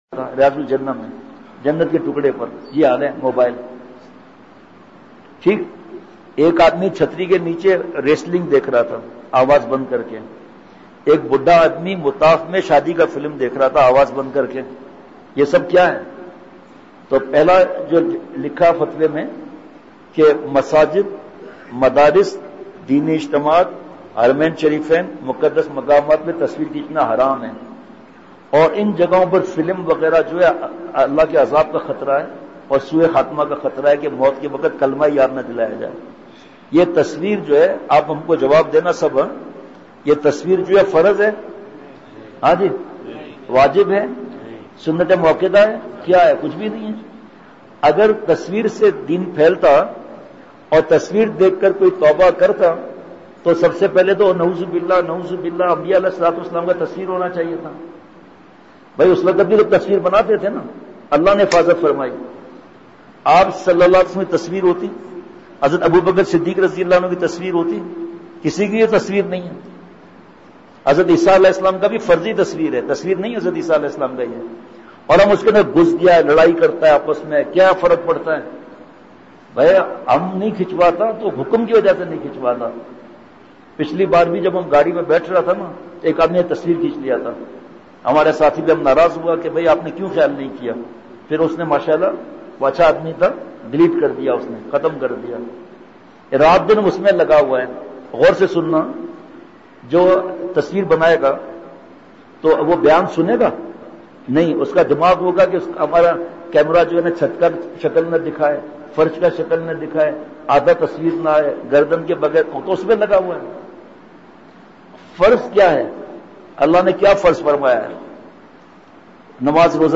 بمقام: بعد از ظہر مدینہ مسجد چمن بعد ظہر بیان مارکیٹ کے درمیان مسجد تھی۔